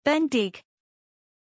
Prononciation de Bendik
pronunciation-bendik-zh.mp3